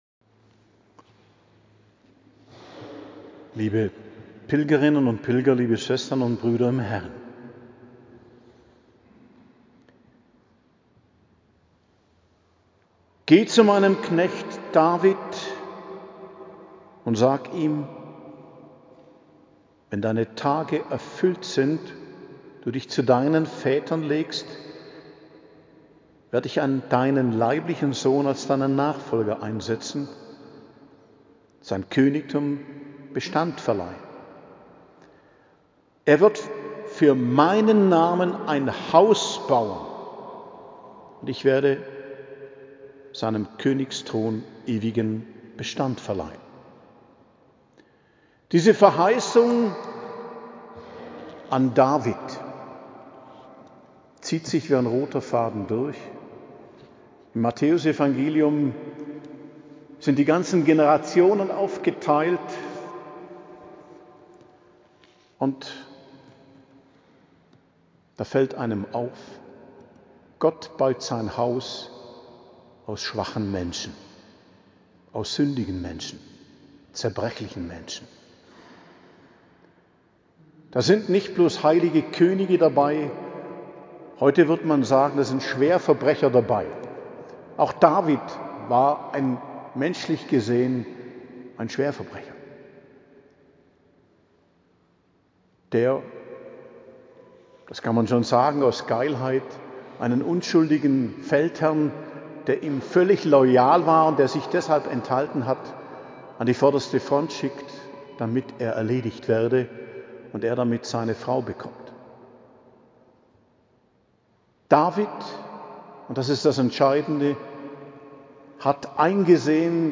Predigt am Hochfest des Hl. Josef, 19.03.2026 ~ Geistliches Zentrum Kloster Heiligkreuztal Podcast